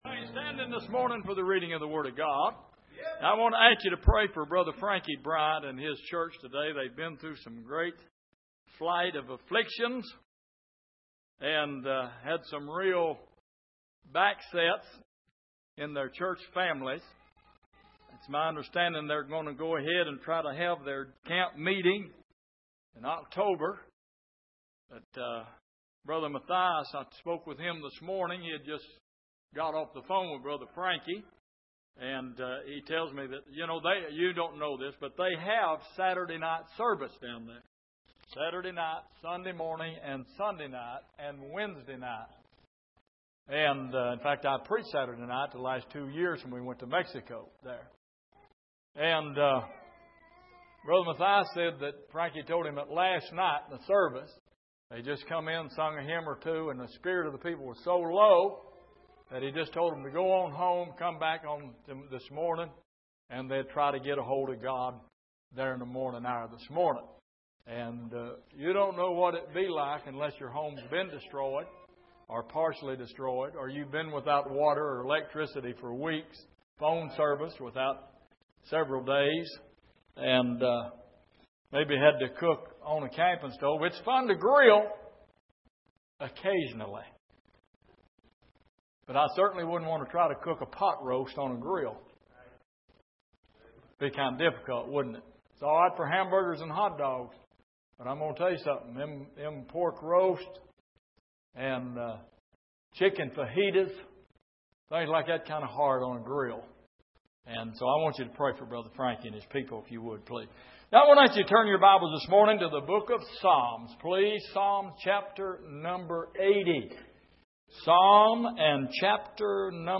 Exposition of the Psalms Passage: Psalm 80:1 Service: Sunday Morning Follow The Leader « Finding Help In The Hills God’s Good Things